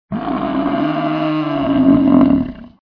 Звуки бизона
Второй вариант му с более продолжительным временем